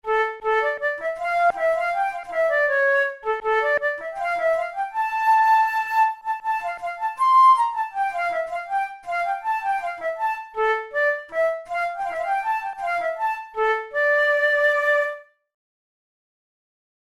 InstrumentationFlute solo
KeyD minor
RangeA4–C6
Time signature2/2
Tempo80 BPM